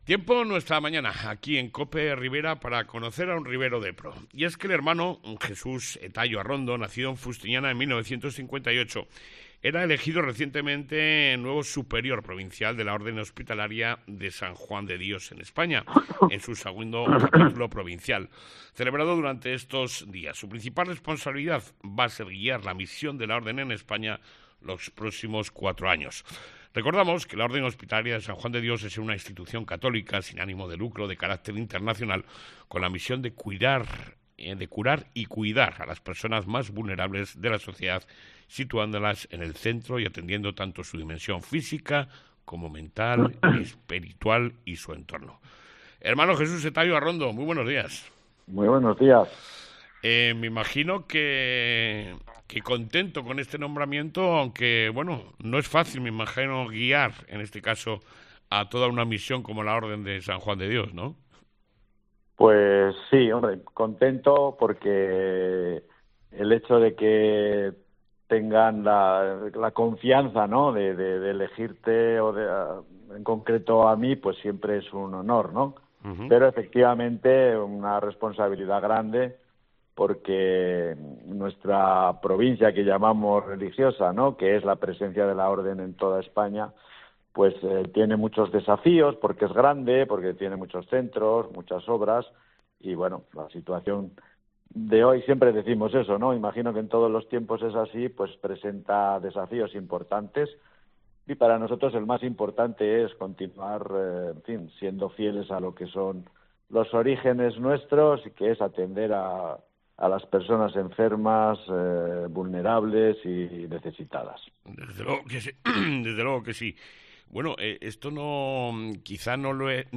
ENLACE A LA ENTREVISTA RADIOFÓNICA